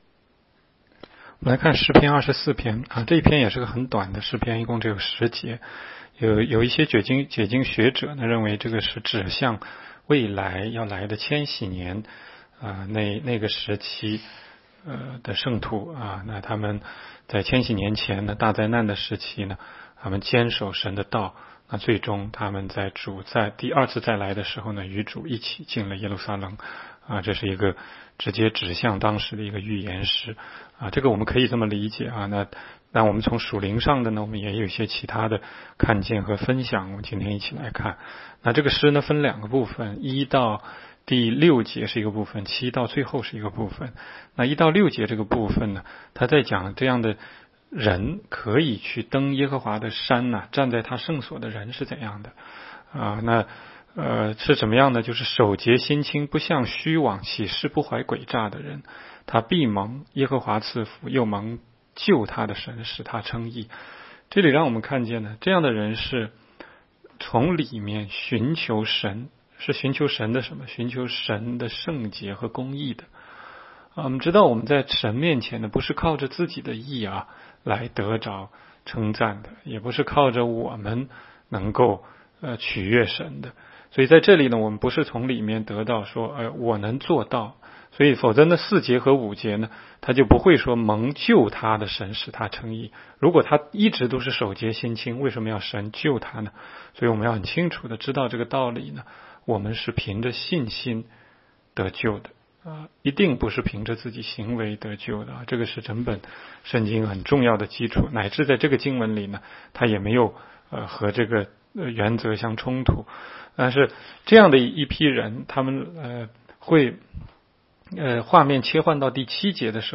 16街讲道录音 - 每日读经-《诗篇》24章